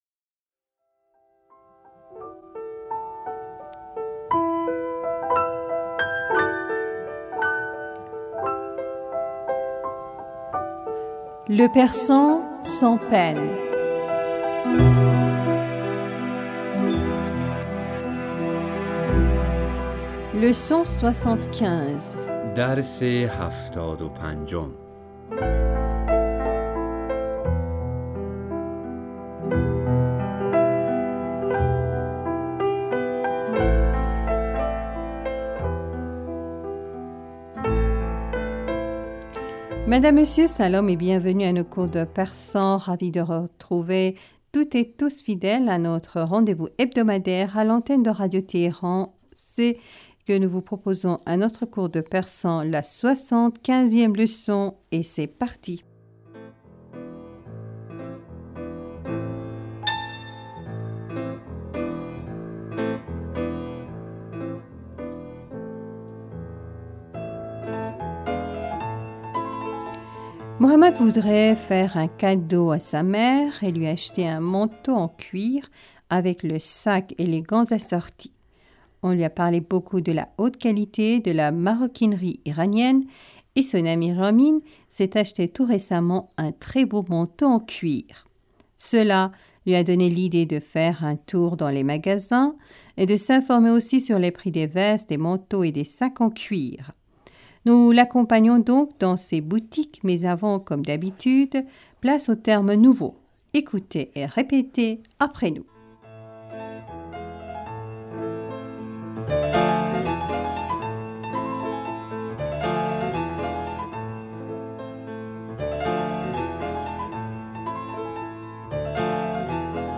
Madame, Monsieur Salam et bienvenus à nos cours de persan.
Ecoutez et répétez après nous.